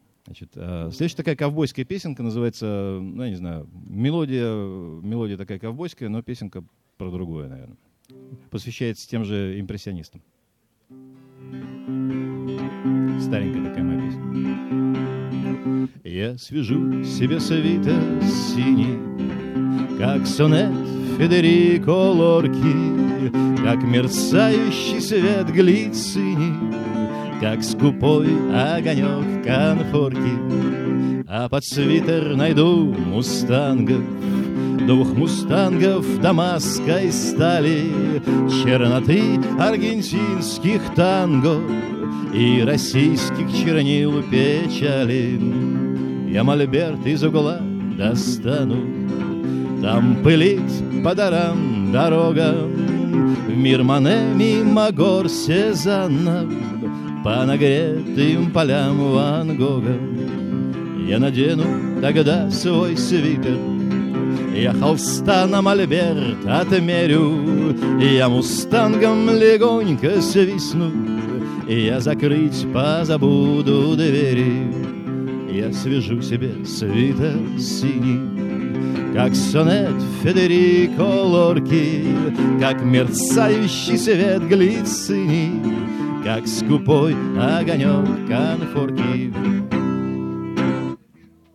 2005 - Граненый слёт